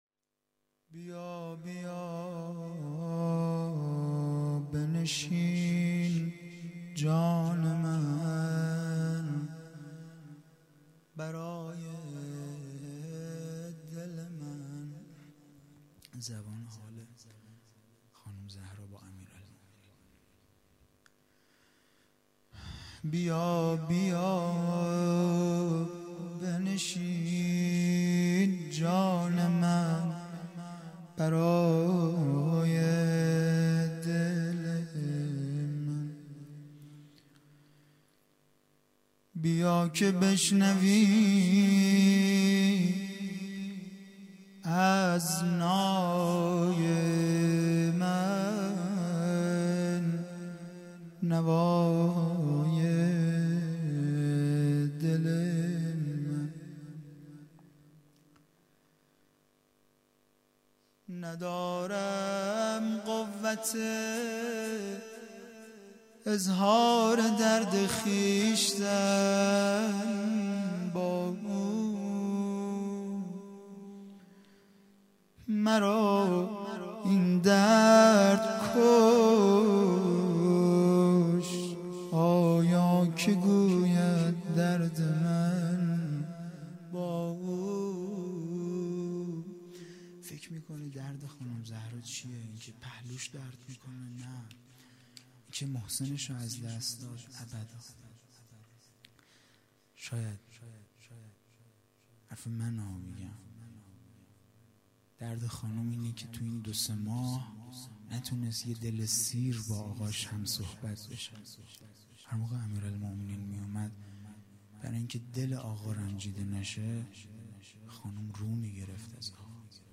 هیئت دانشجویی فاطمیون دانشگاه یزد
روضه پایانی